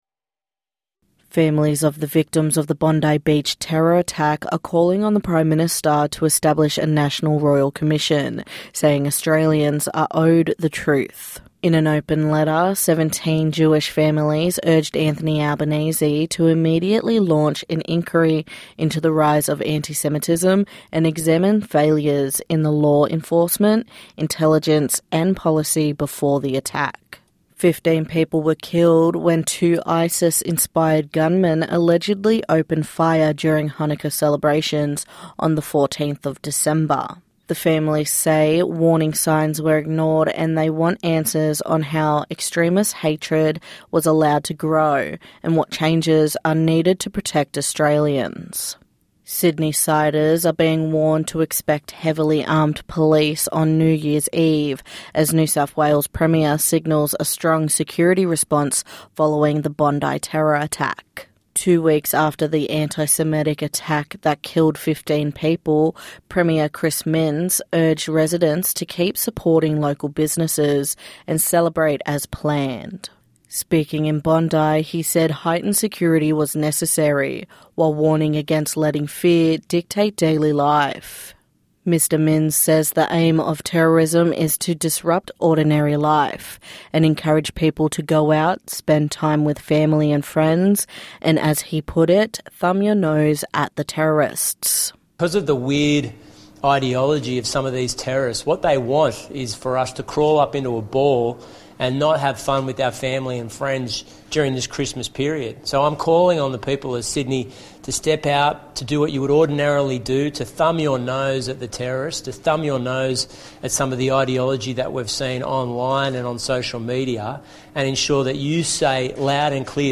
NITV Radio News - 29/12/2025